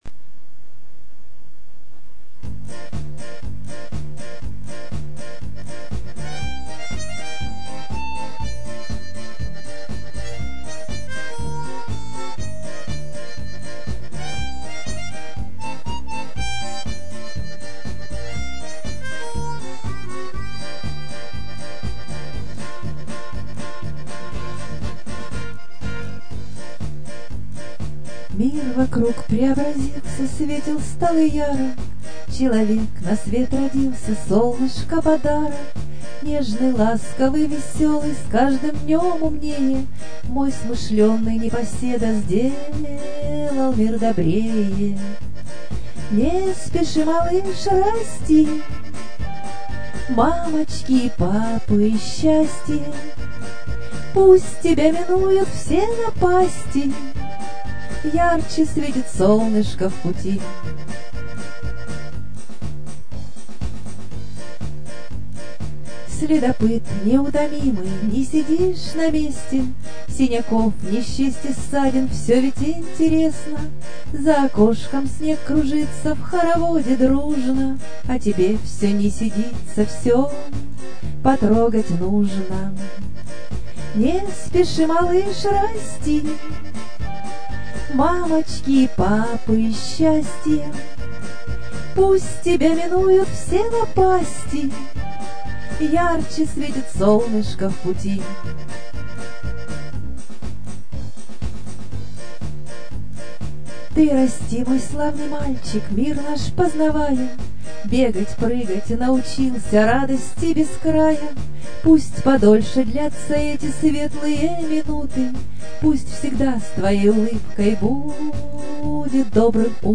Музыка и аккомпонемент
ИСПОЛНЯЮ Я. Эти песню посвящаю своему внуку.